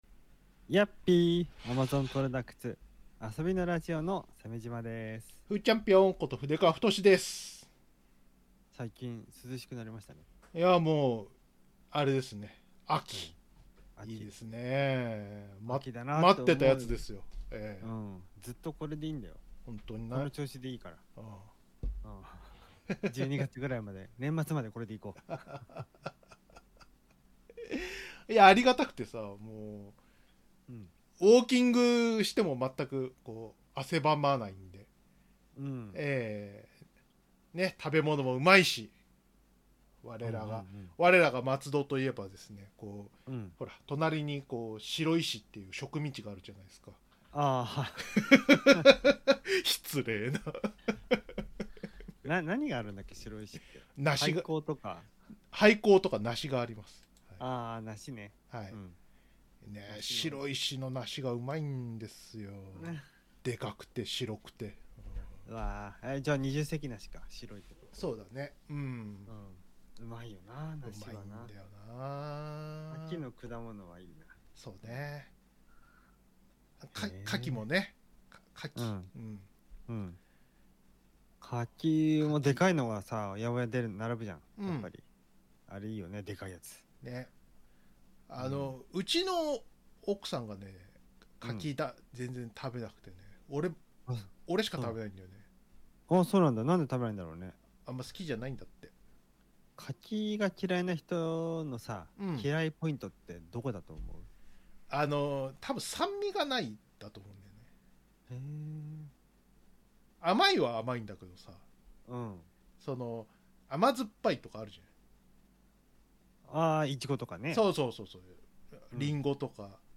日々思ったことをフリートークしました！自民党総裁選 江口寿史トレス問題